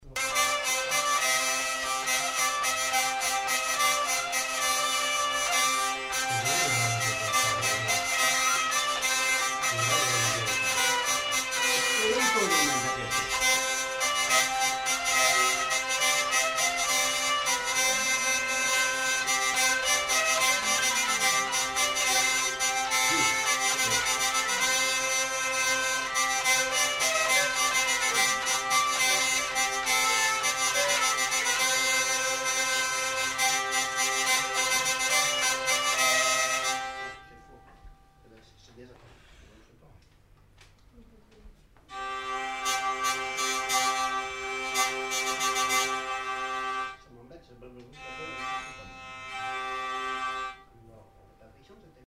Valse
Lieu : Pyrénées-Atlantiques
Genre : morceau instrumental
Instrument de musique : vielle à roue
Danse : valse
Notes consultables : L'interprète non identifié ne termine pas le morceau.